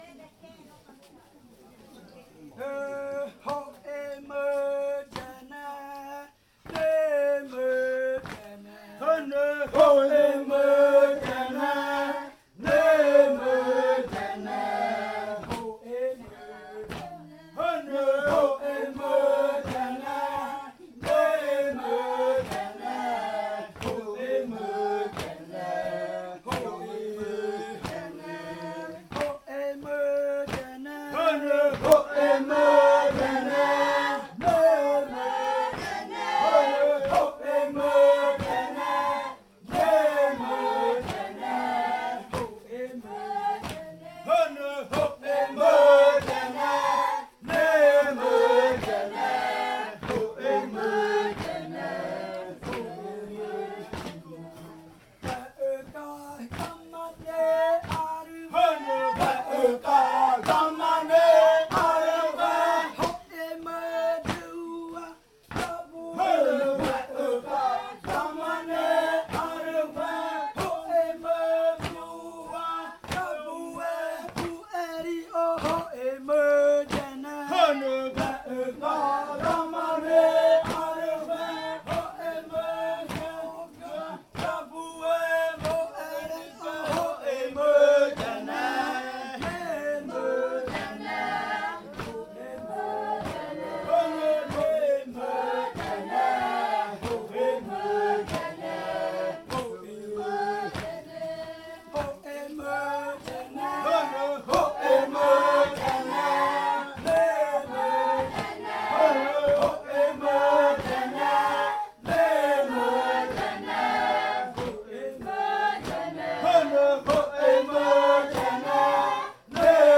Canto de amanecida de la variante muruikɨ
Leticia, Amazonas
con el grupo de cantores bailando en la Casa Hija Eetane.
with the group of singers dancing at Casa Hija Eetane.